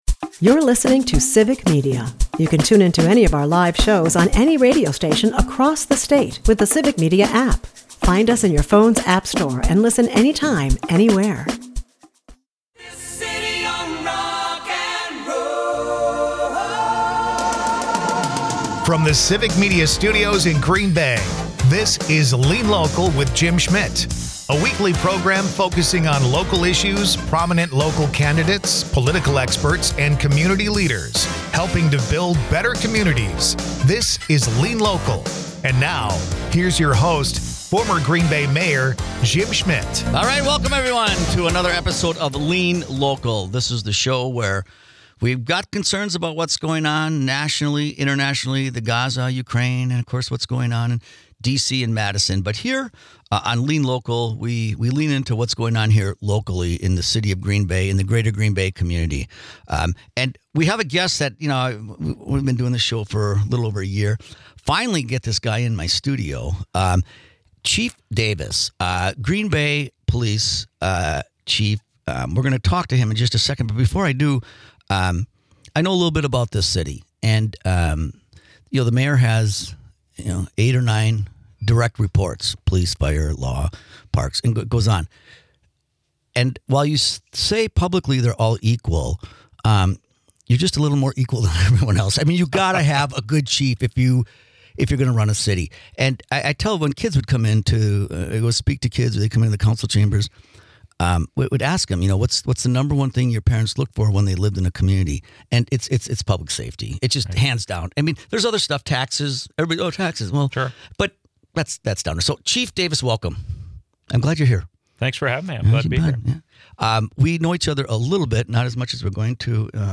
Former Green Bay Mayor, Jim Schmitt is joined by Green Bay Chief of Police, Chris Davis. Chris talks about his background before he moved to Green Bay, why he chose Green Bay, and the Police resources. Then Jim moves the topic over to homelessness in Green Bay and what the Chief and the police are doing in order to prevent.
Lean Local is a part of the Civic Media radio network and airs Sunday's from 1-2 PM on WGBW .